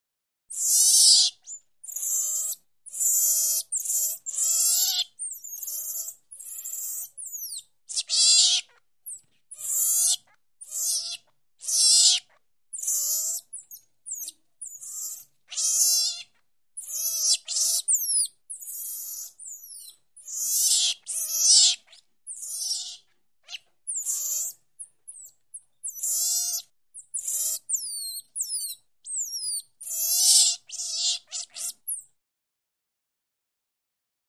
Monkey, Red belly Calls, Peeps. High Pitched Squeals Chirping And Whining Sound. Close Perspective